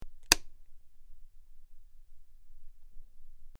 SwitchClicksOnOff PE447605
Switch; Clicks On And Off.